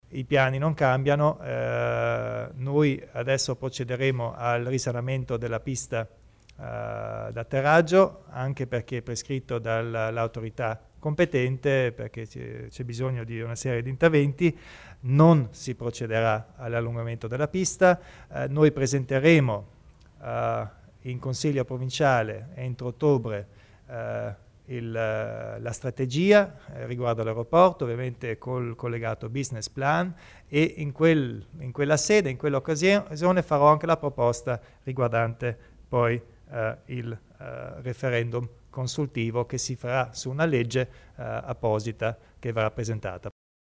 Il Presidente Kompatscher spiega il futuro dell'aeroporto